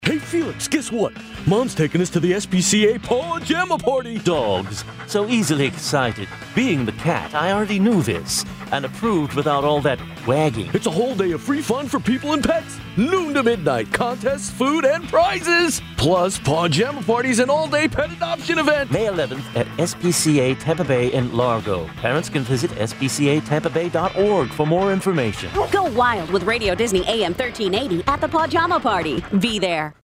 Download Nonprofit Organization Radio Spot
Pinstripe_SPCA_Radio Spot.mp3